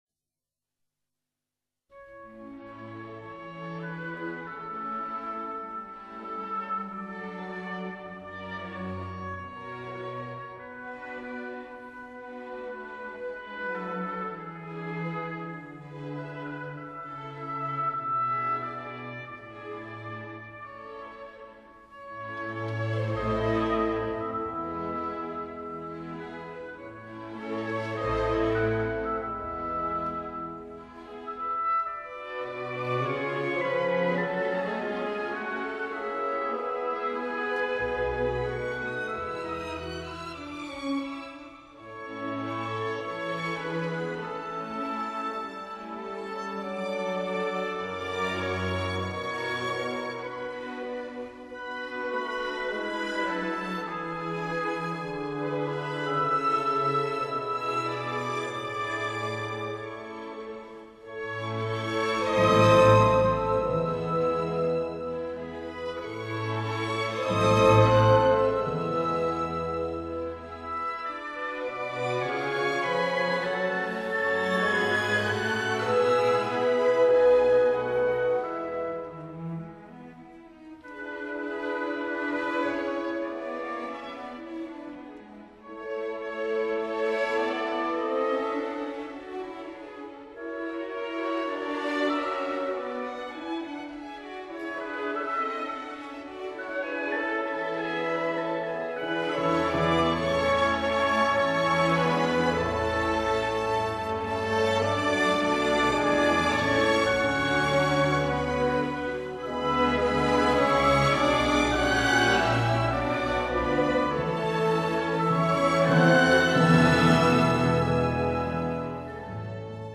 Adagio    [0:05:29.14]